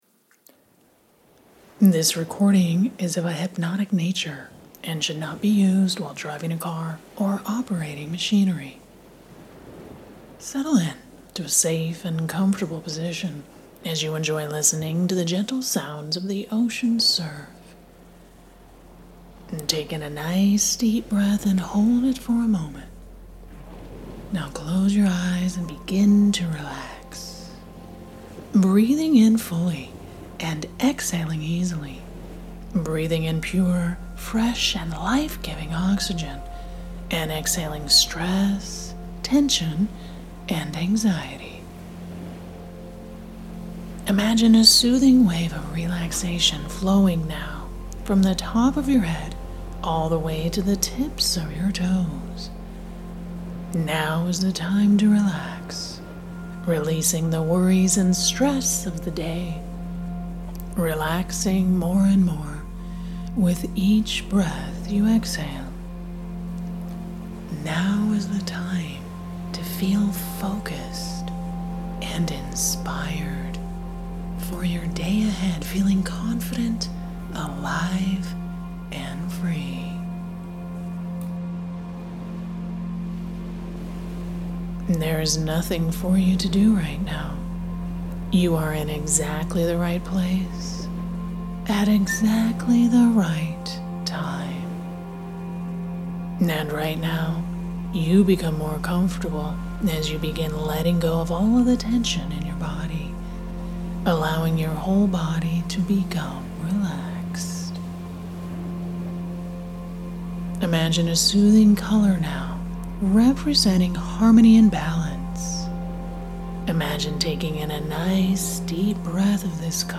Empowering Morning Hypnosis MP3 FREE
Empowering-Morning-Hypnosis.mp3